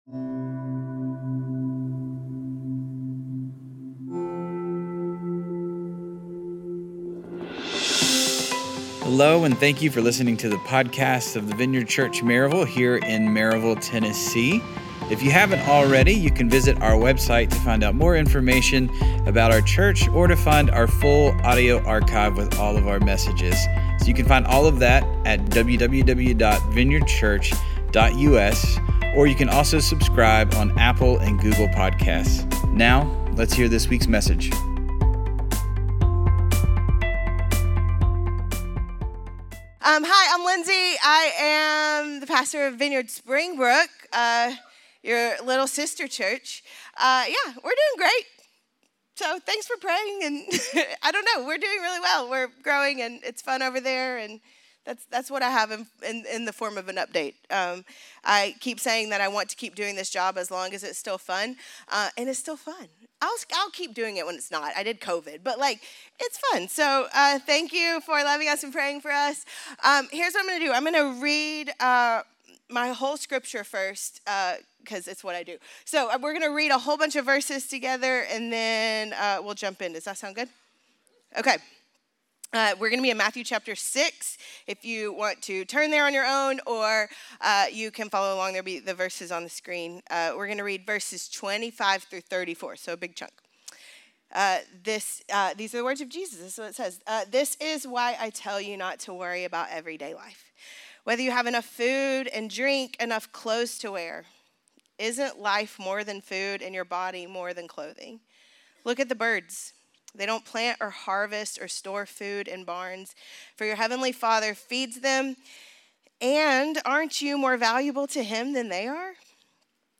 A sermon about our deeply held belief in the joy and freedom that come from valuing and practicing simplicity.